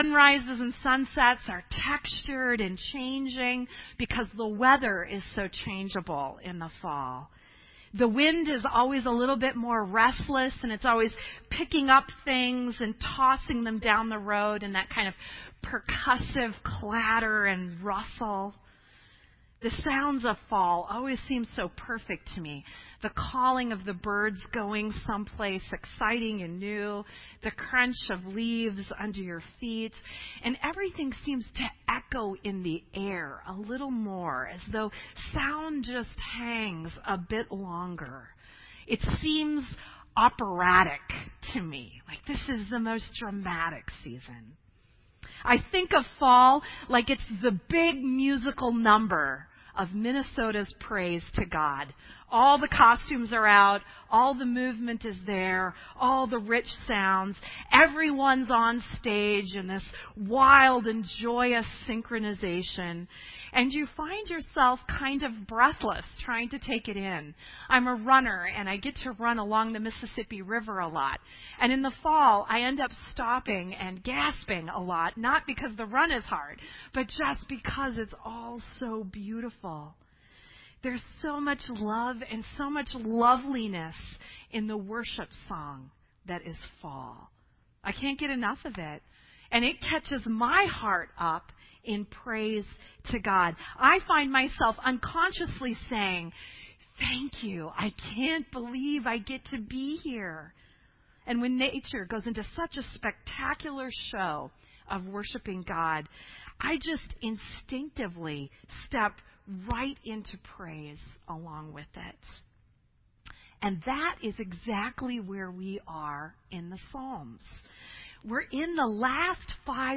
This entry was posted in Sermon Audio on August 26